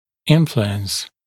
[‘ɪnfluəns][‘инфлуэнс]влияние; влиять